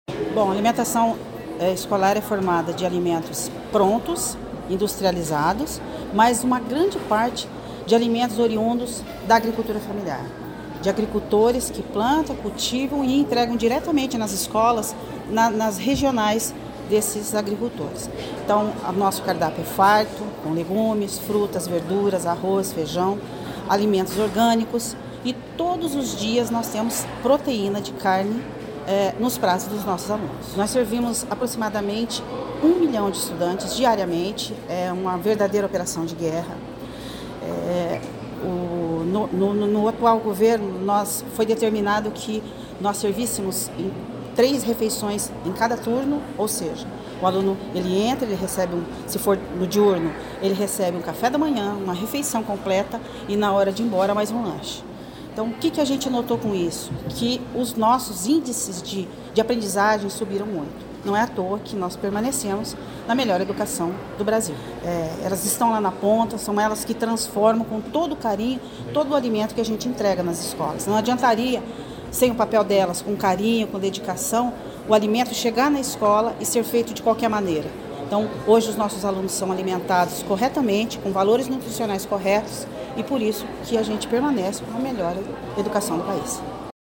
Sonora da diretora-presidente do Fundepar, Eliane Teruel Carmona, sobre sobre a aula da chef Manu Buffara para Merendeiras do Paraná